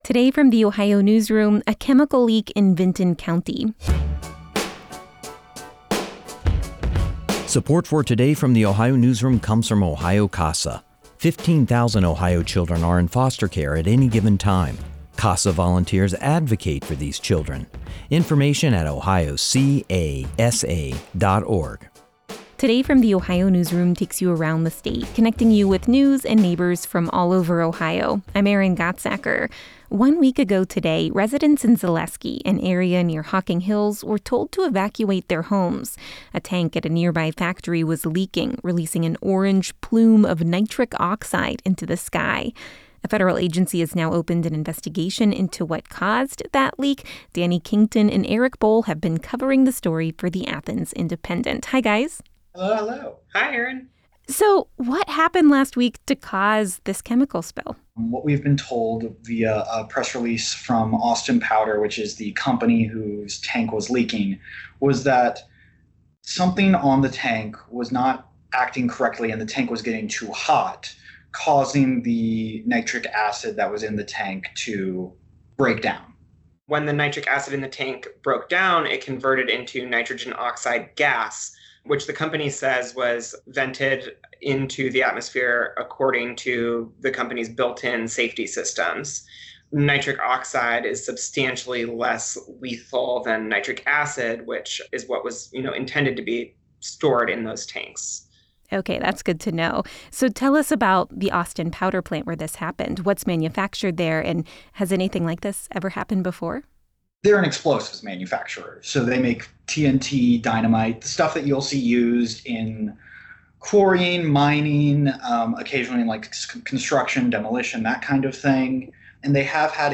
This interview has been lightly edited for brevity and clarity.